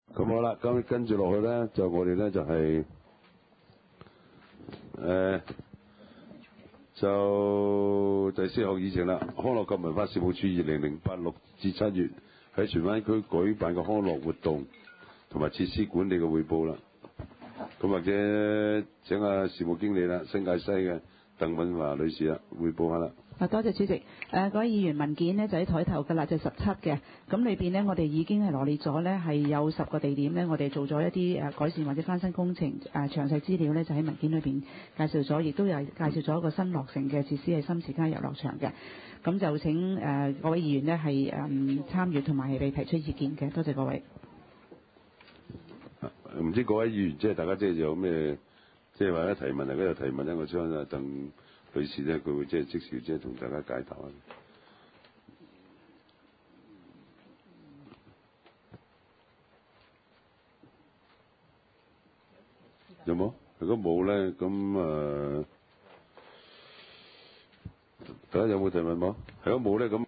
地區設施管理委員會第五次會議
荃灣民政事務處會議廳